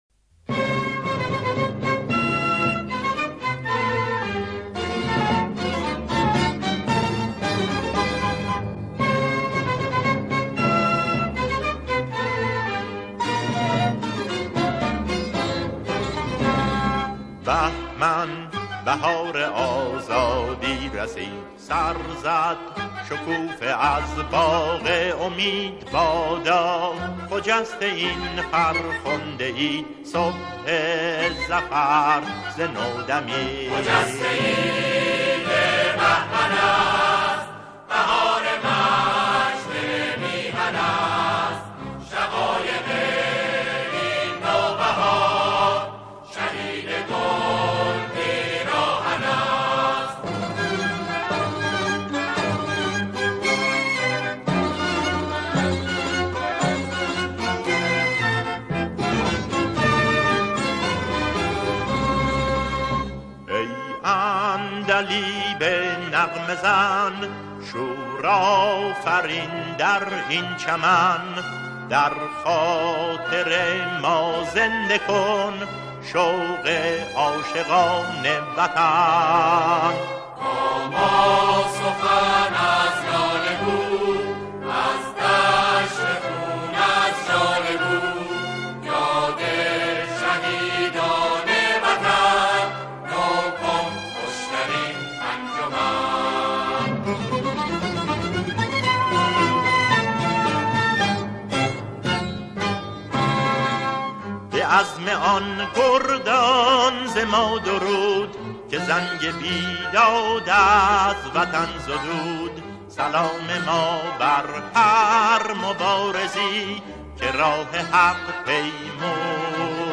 سرود نوستالژی